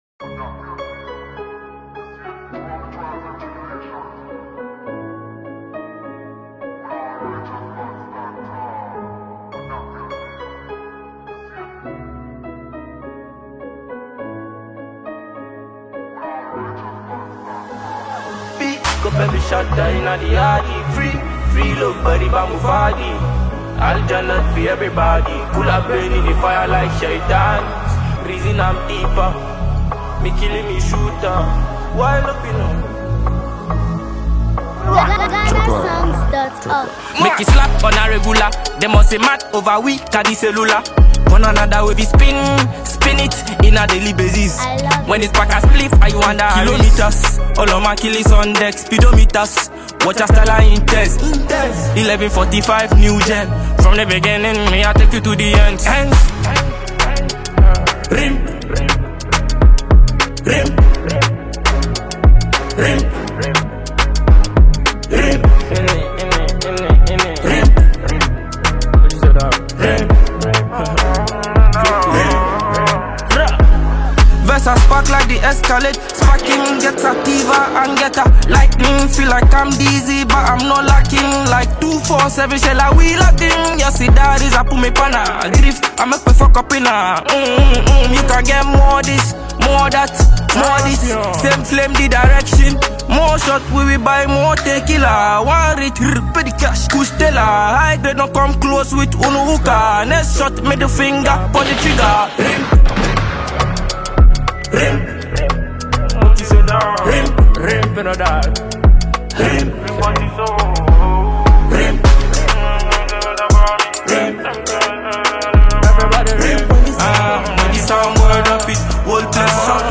Afro-dancehall and conscious music